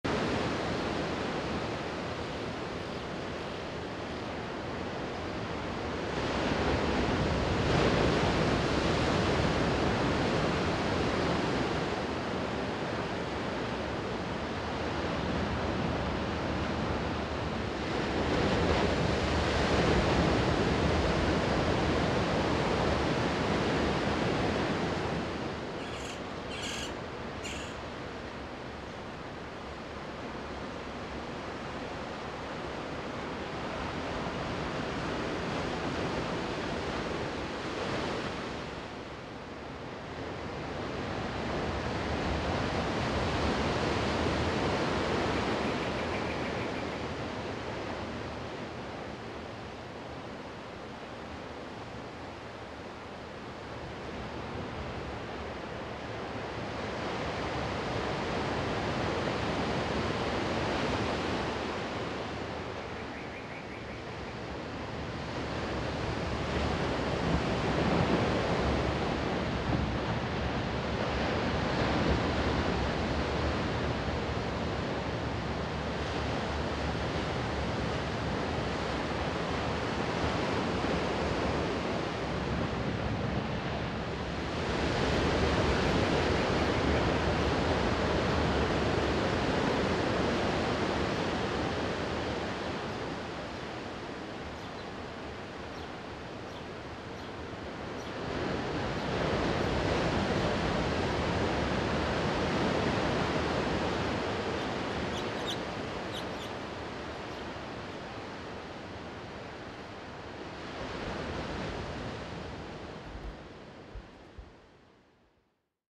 Sonido-del-mar-relajante-HD-Imágenes-de-las-olas-en-la-playa.-Sound-of-the-sea-Waves-Relax-vis.mp3